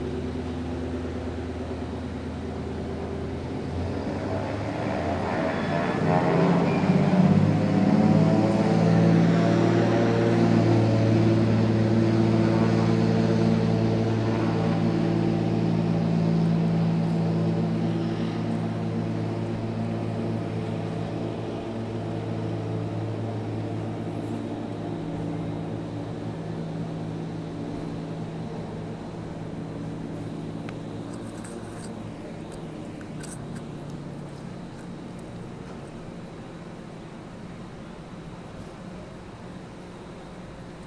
Seaplane Takeoff Vancouver 2010